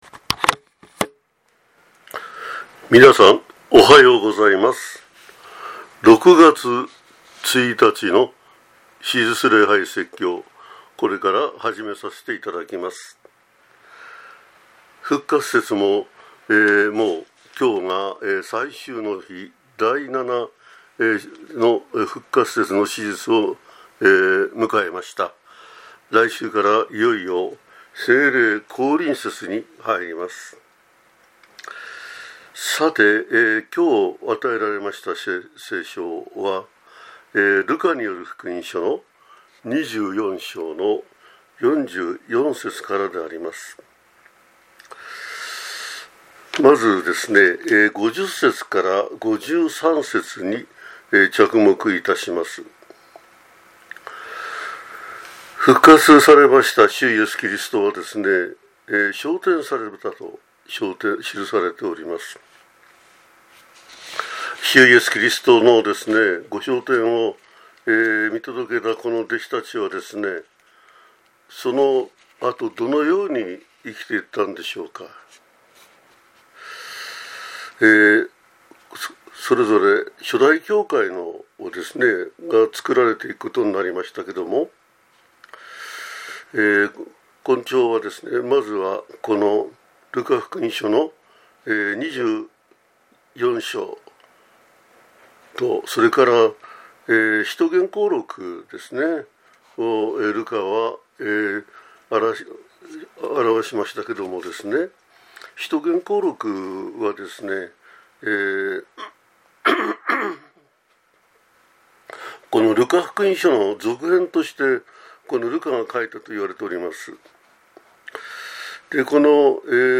2025年6月1日（復活節第7主日）